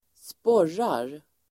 Uttal: [²sp'år:ar]